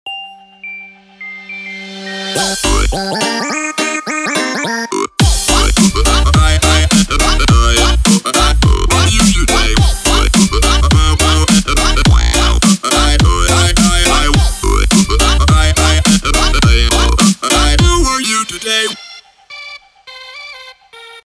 громкие
веселые
энергичные
Dubstep
glitch hop
Веселая музыка для звонка